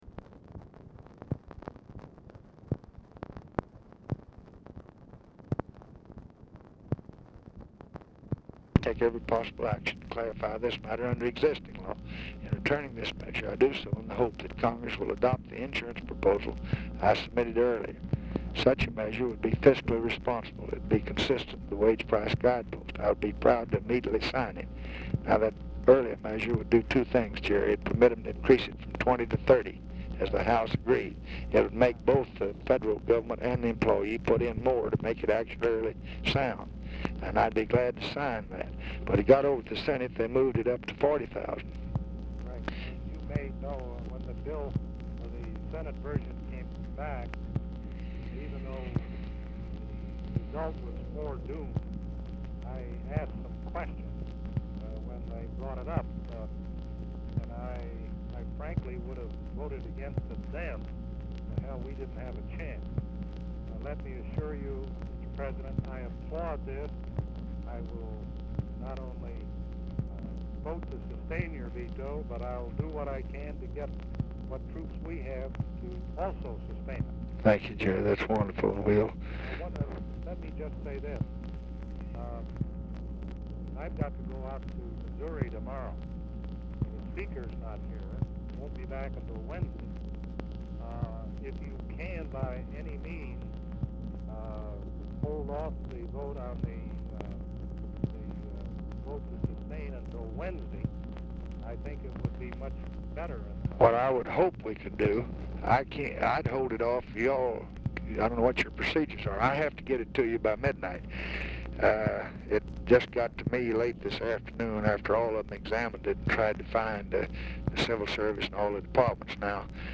Telephone conversation # 10755, sound recording, LBJ and GERALD FORD
CONTINUES FROM PREVIOUS RECORDING; FORD IS ALMOST INAUDIBLE
Format Dictation belt